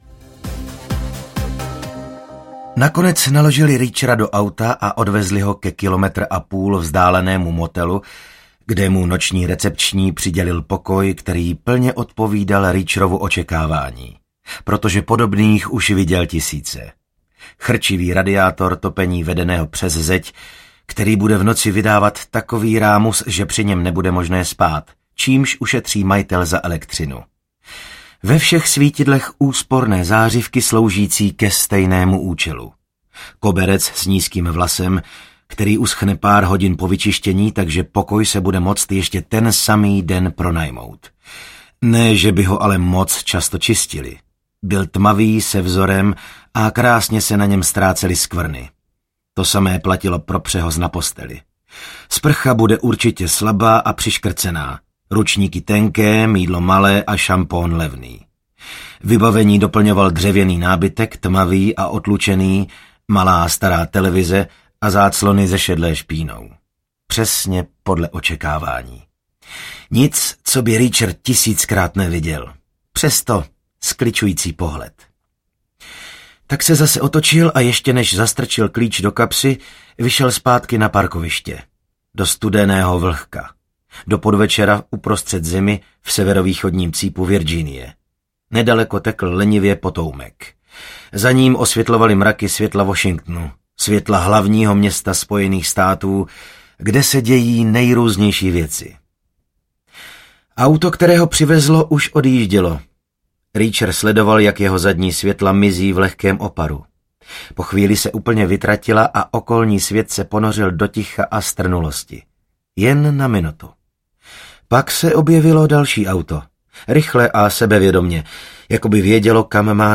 Jack Reacher: Nevracej se audiokniha
Ukázka z knihy